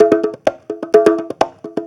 Conga Loop 128 BPM (26).wav